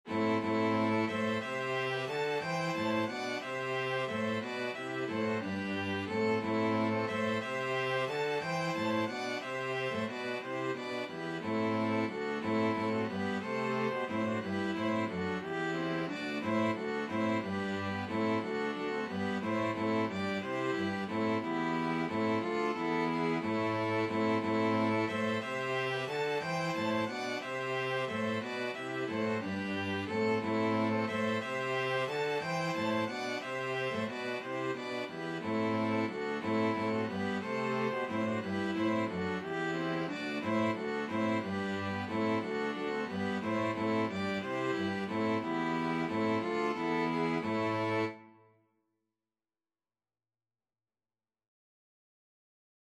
Free Sheet music for String Quartet
Violin 1Violin 2ViolaCello
6/4 (View more 6/4 Music)
E minor (Sounding Pitch) (View more E minor Music for String Quartet )
Classical (View more Classical String Quartet Music)